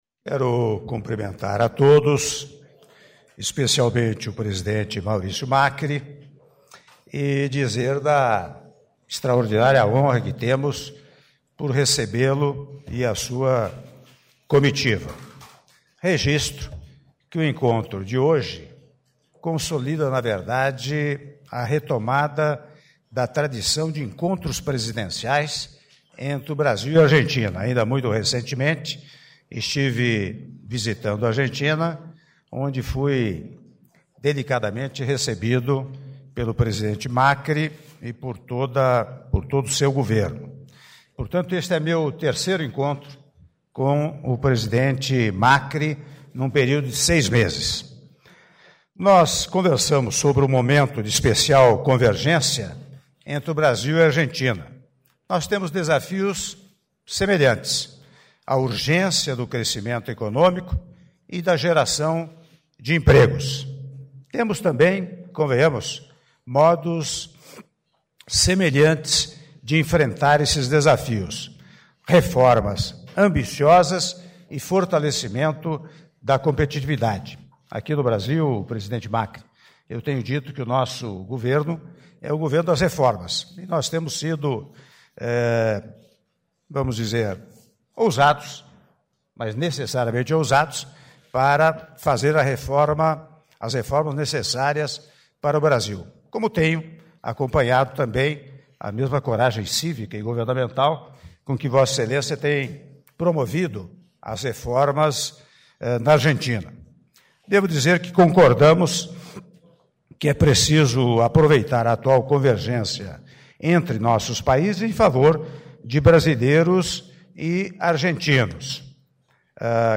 Áudio da Declaração à Imprensa do presidente da República, Michel Temer, após Assinatura de atos - (06min41s) - Brasília/DF